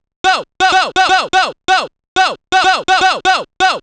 cch_vocal_go_125.wav